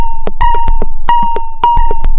这个循环有很好的电子感和典型的较高频率的钟声般的频率调制内容。大部分是较高的频率和一些漂亮的较高的合成器汤姆声。节奏为110 bpm，持续1个4/4度。
Tag: 110-BPM 电子 FM 有节奏